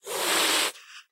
hiss2.ogg